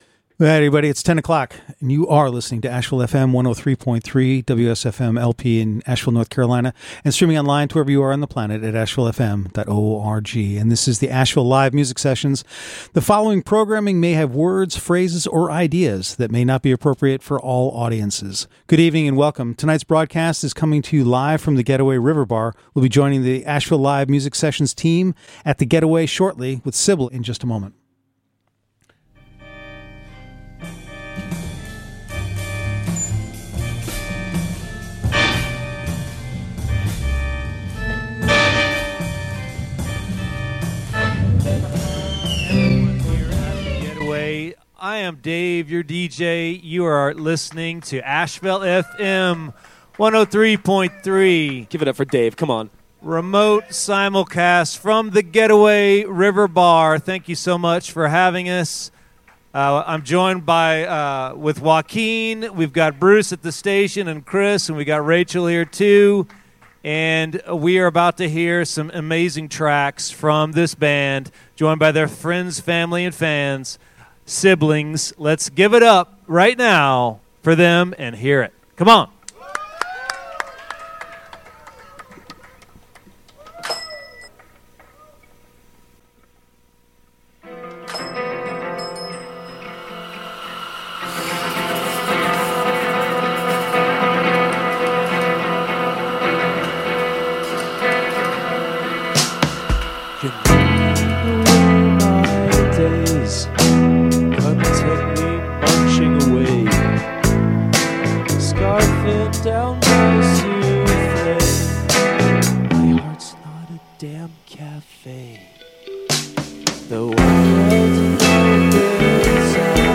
Live from The Getaway River Bar